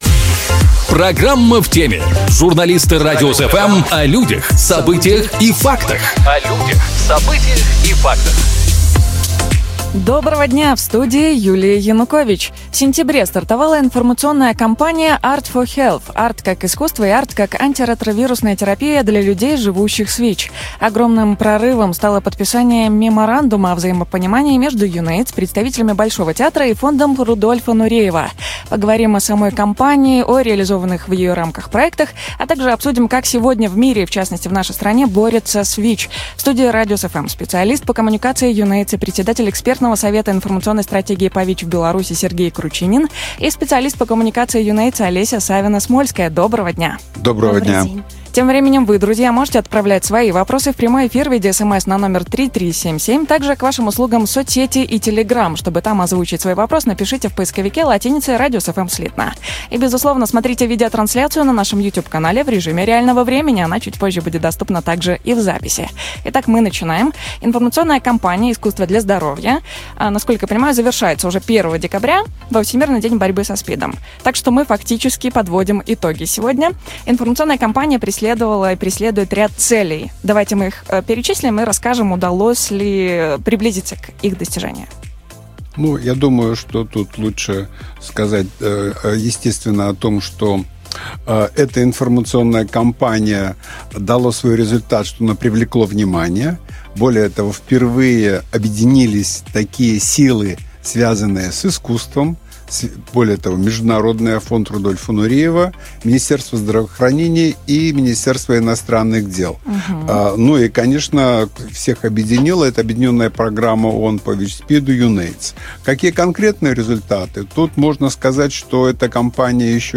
В студии «Радиус FМ»